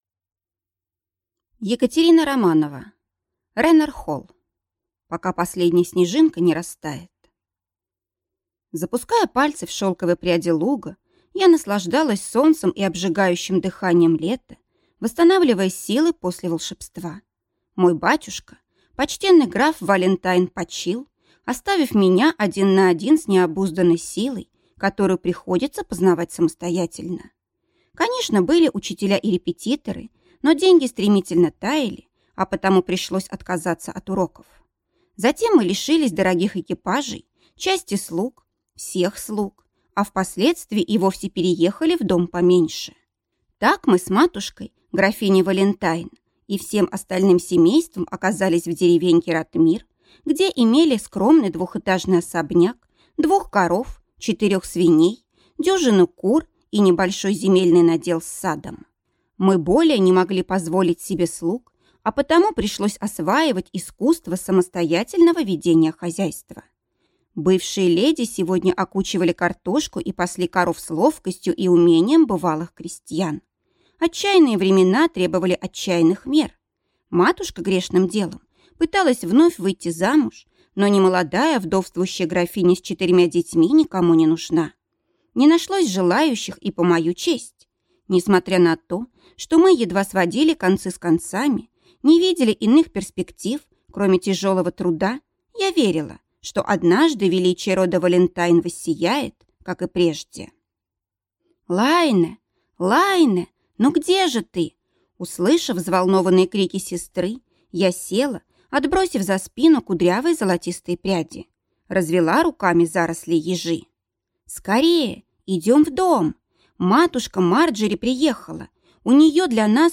Аудиокнига Ренар Холл | Библиотека аудиокниг